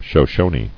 [Sho·sho·ne]